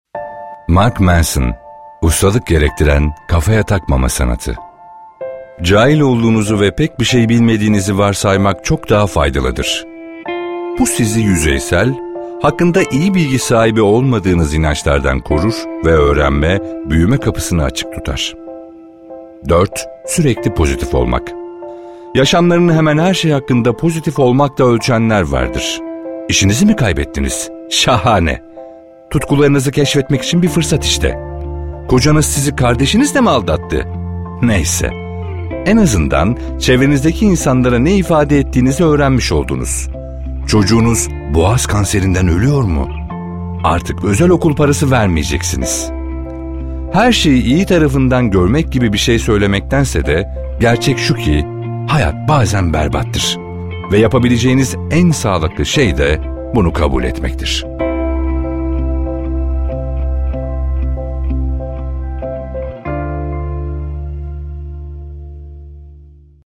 كتاب التعليق الصوتي
3. تسجيل صوت: للحصول على جودة صوت ممتازة ، نستخدم بيئة الاستوديو لتسجيل الصوت الاحترافي.